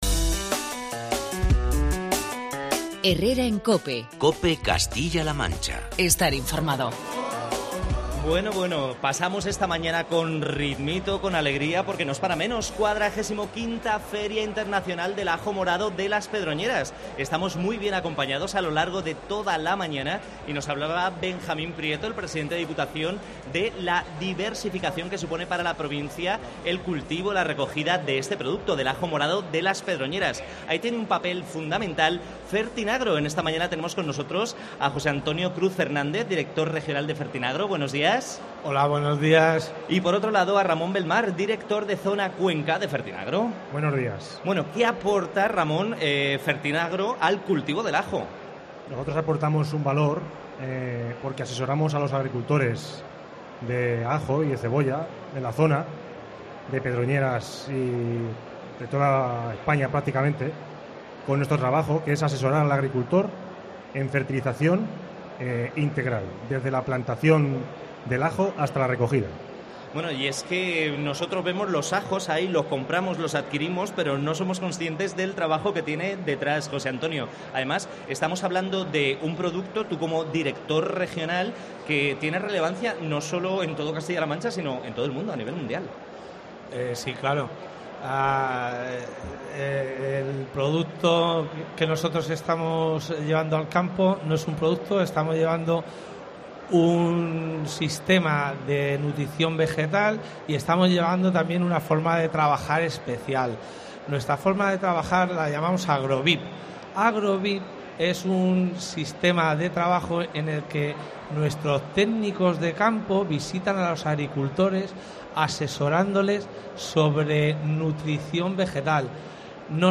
COPE Castilla-La Mancha se ha trasladado a la XLV edición de la Feria Internacional del Ajo de las Pedroñeras para acercar a todos los castellanos manchegos las propiedades de este producto tan nuestro, desde la denominada "Capital Mundial del Ajo", desde las Pedroñeras (Cuenca).